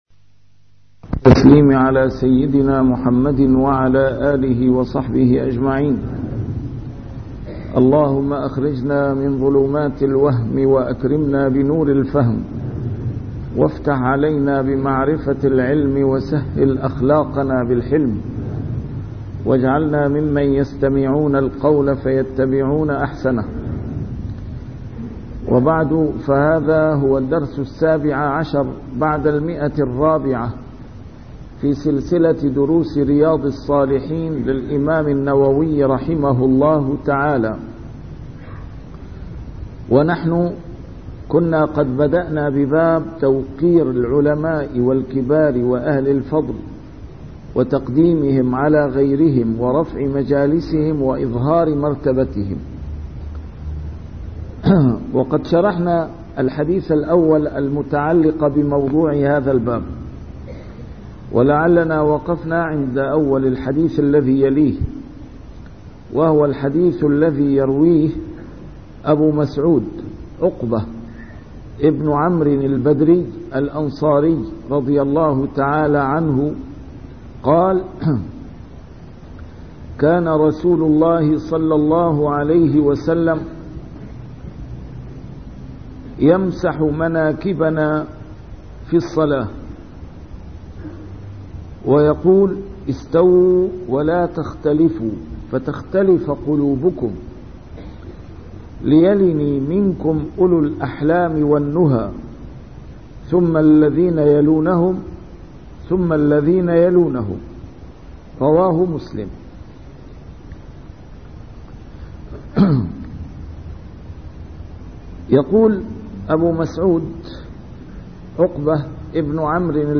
A MARTYR SCHOLAR: IMAM MUHAMMAD SAEED RAMADAN AL-BOUTI - الدروس العلمية - شرح كتاب رياض الصالحين - 417- شرح رياض الصالحين: توقير العلماء